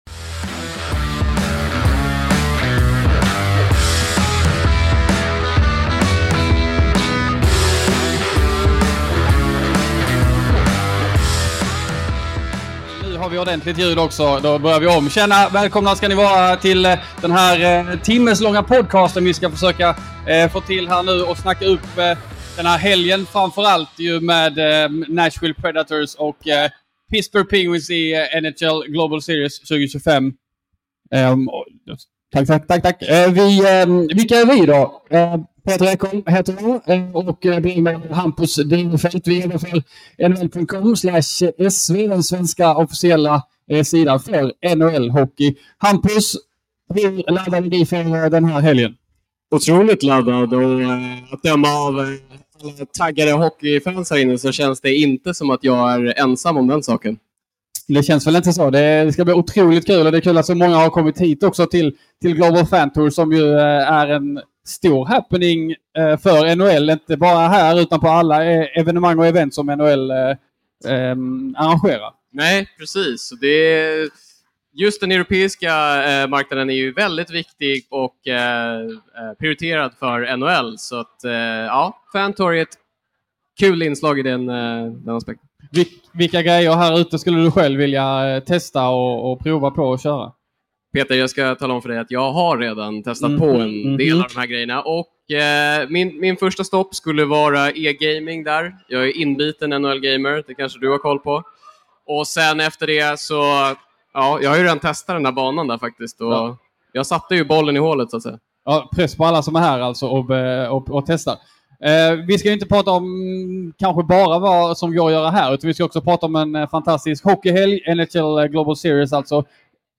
Längs Sargen var på plats på NHL Global Fan Tour i Kungsträdgården för en livepodd.